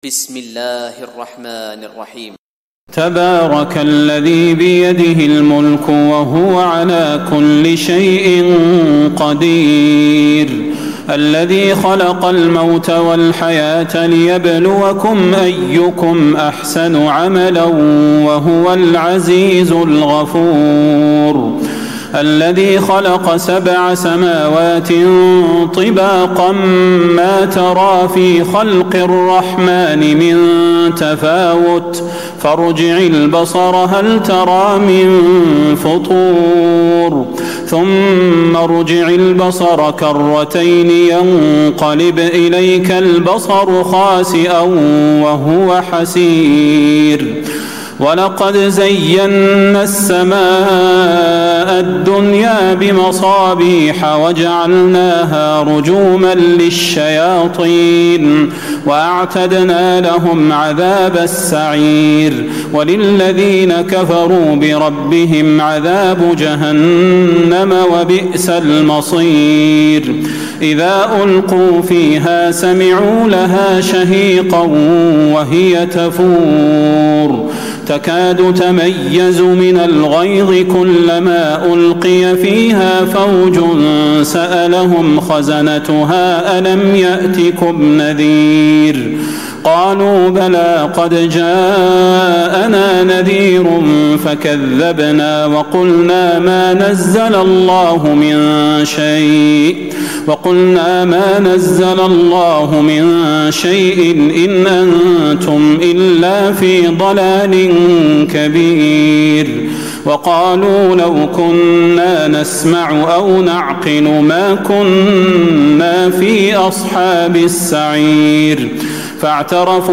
تراويح ليلة 28 رمضان 1436هـ من سورة الملك الى نوح Taraweeh 28 st night Ramadan 1436H from Surah Al-Mulk to Nooh > تراويح الحرم النبوي عام 1436 🕌 > التراويح - تلاوات الحرمين